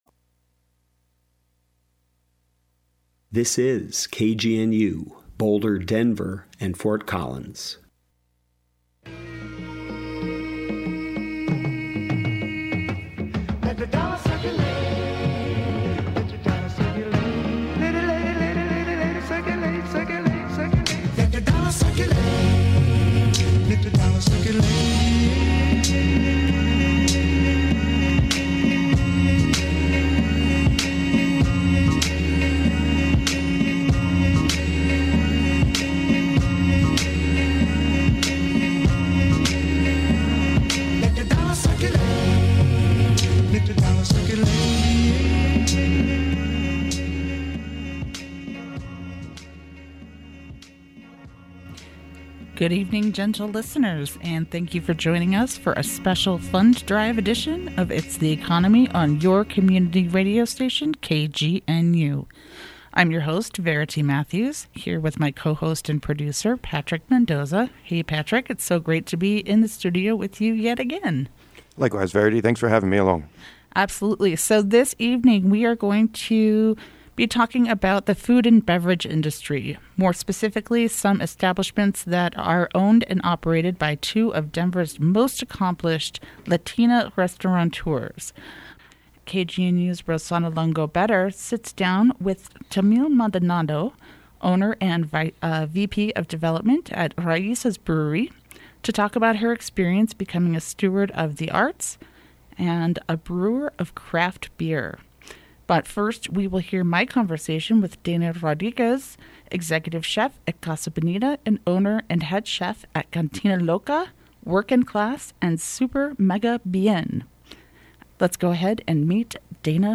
In this episode of It’s The Economy we hear a conversation